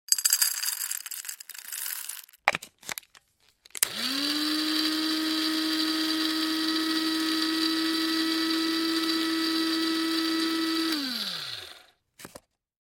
Звуки кофемолки
Засыпали закрыли крышку включили сняли крышку